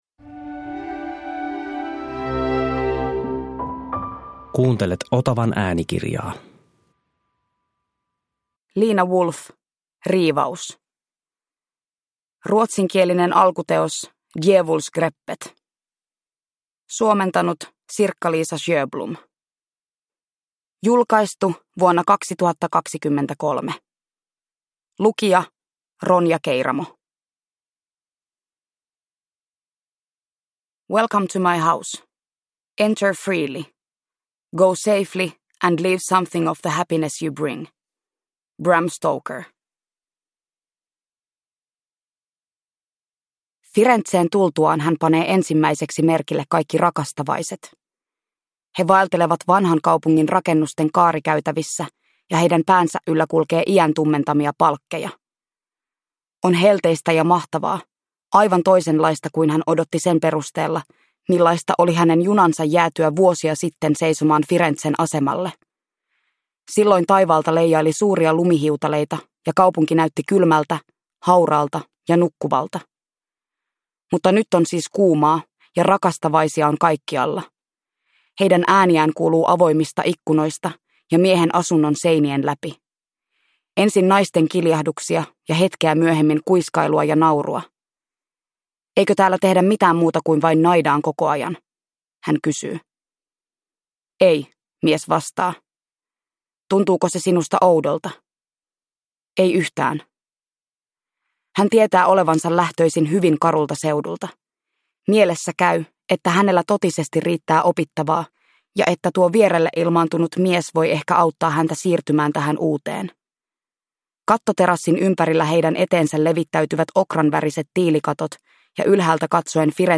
Riivaus – Ljudbok – Laddas ner